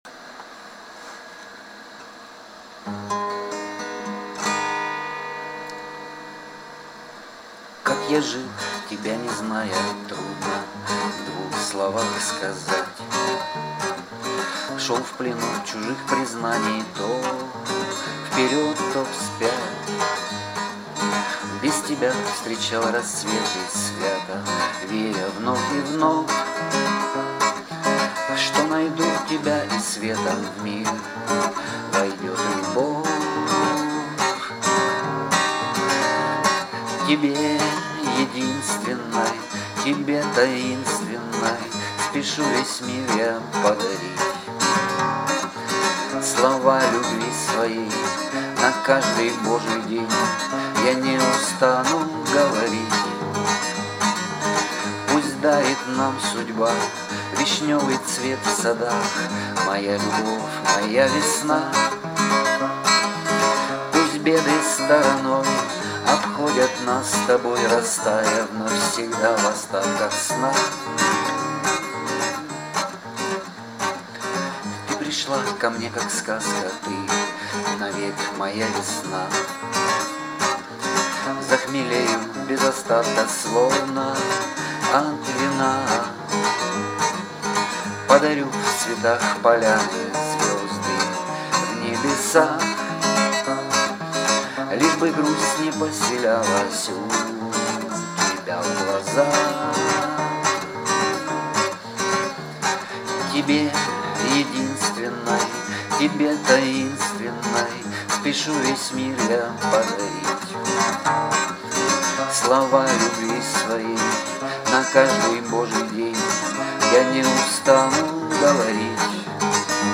у Вас красивый голос и стихи чудесные flo10
с удовольствием послушала авторское исполнение 22 22